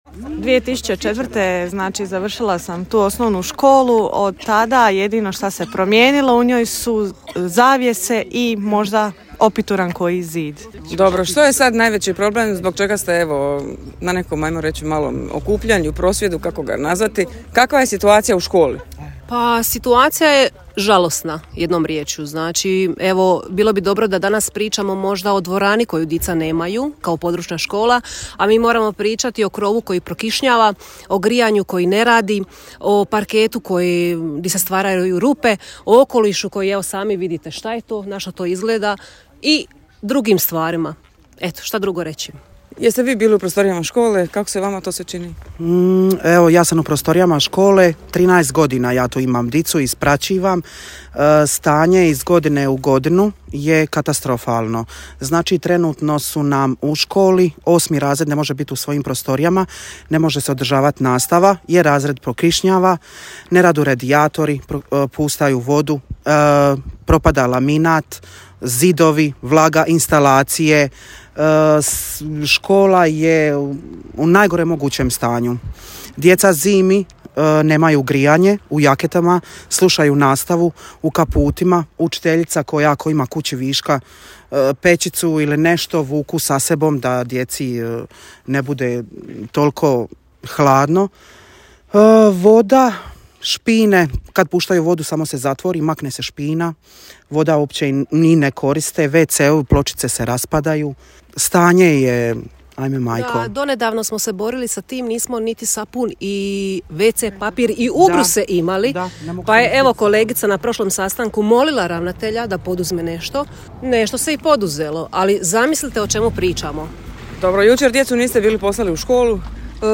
Za Radio Drniš jutros ispred škole u Gradcu su kazali kako se u školu dvadeset godina nije ulagalo.